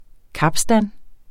kapstan substantiv, fælleskøn Bøjning -en, -er, -erne Udtale [ ˈkɑbsdan ] Oprindelse fra engelsk capstan med samme betydning via fransk cabestan fra latin capere 'gribe om, holde' Betydninger 1.